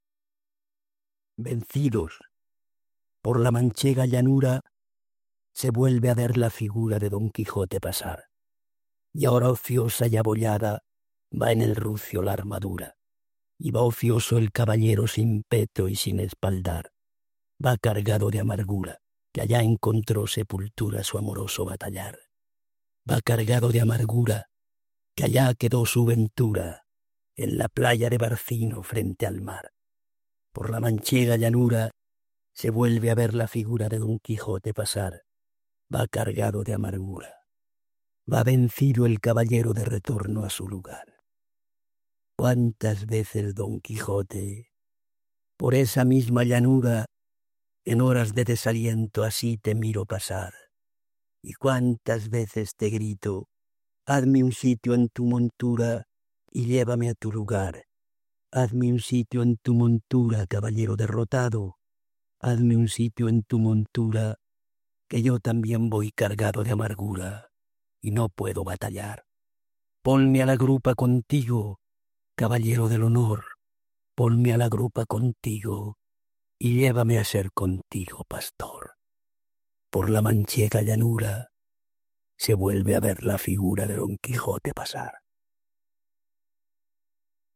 Leon-Felipe.-Vencidos-enhanced-autor-v2.mp3